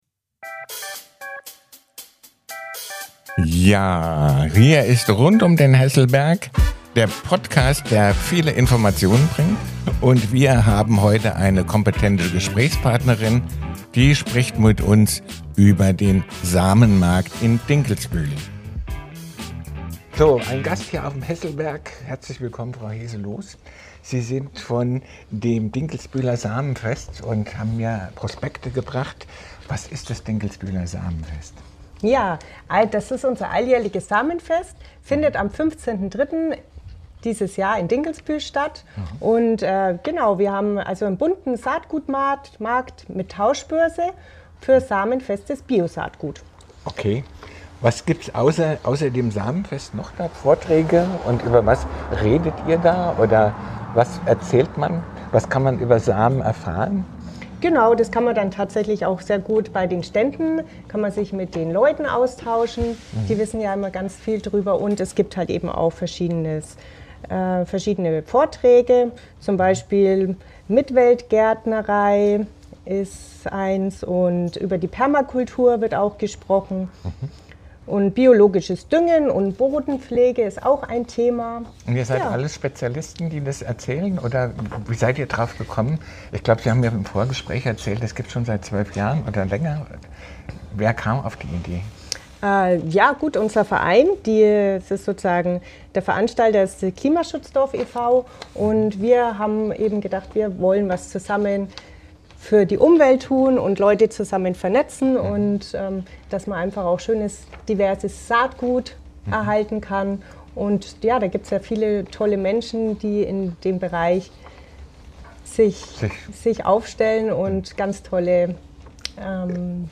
In dieser Folge spreche ich mit einer engagierten Expertin vom